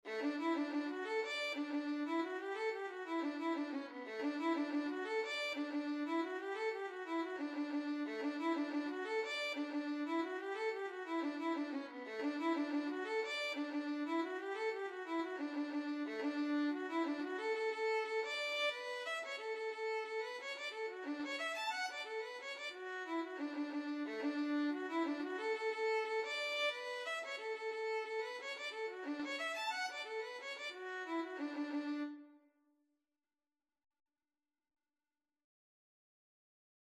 Violin version
6/8 (View more 6/8 Music)
D major (Sounding Pitch) (View more D major Music for Violin )
Violin  (View more Intermediate Violin Music)
Traditional (View more Traditional Violin Music)
Irish
jack_of_all_trades_ON936_VLN.mp3